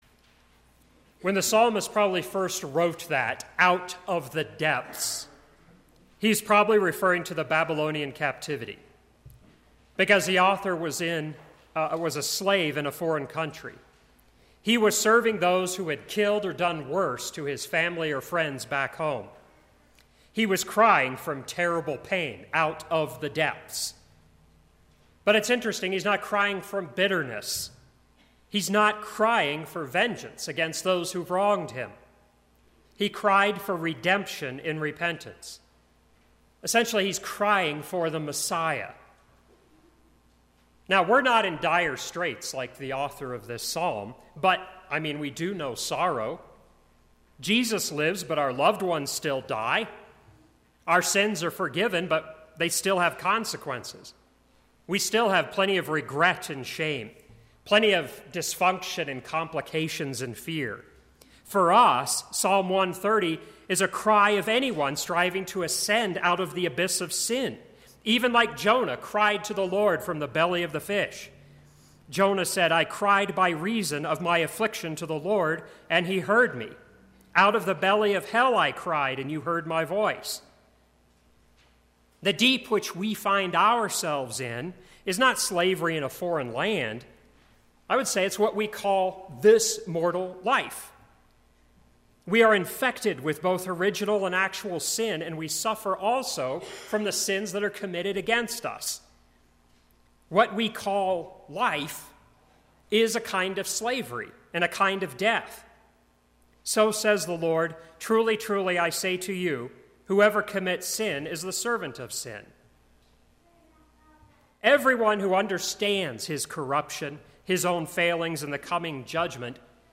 Sermon - 3/29/2017 - Wheat Ridge Lutheran Church, Wheat Ridge, Colorado
Midweek Lenten Service IV